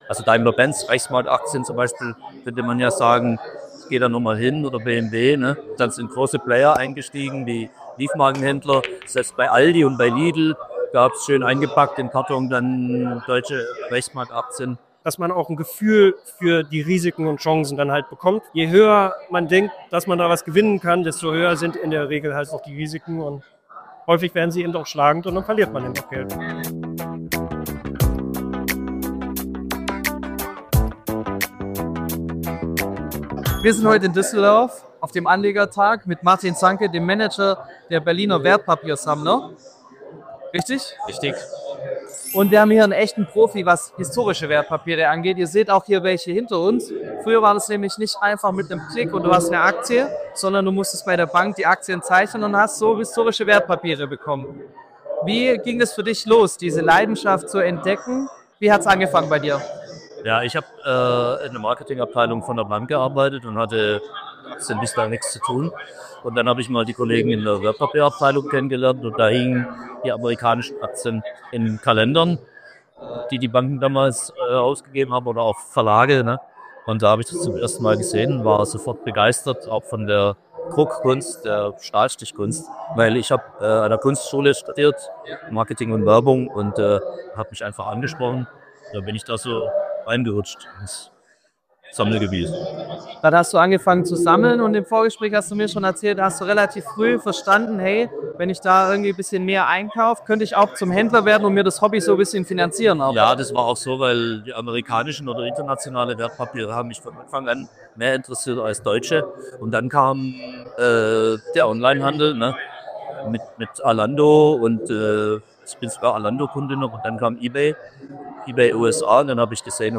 Kurzinterview